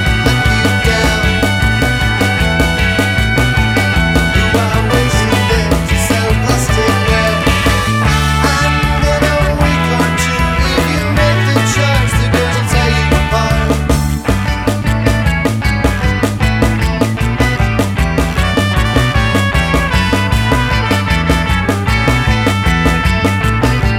No Crowd FX Pop (1960s) 2:12 Buy £1.50